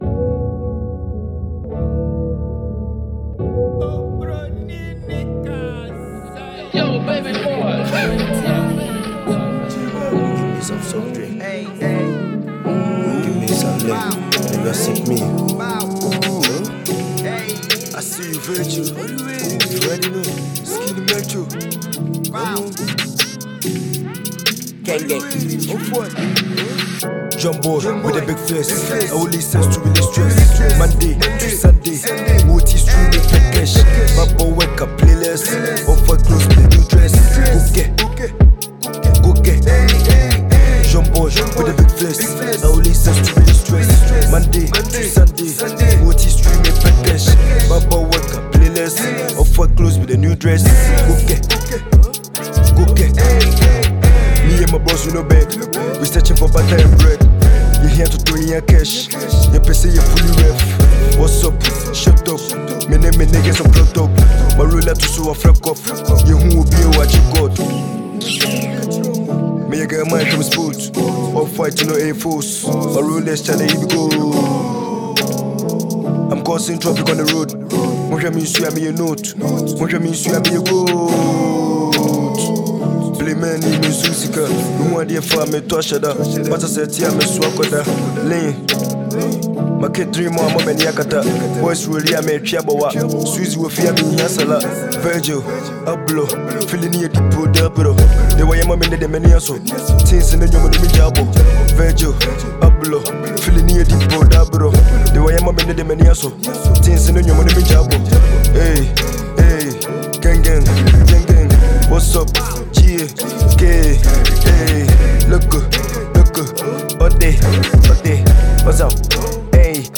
Ghana MusicMusic
Fast rising Ghanaian drillster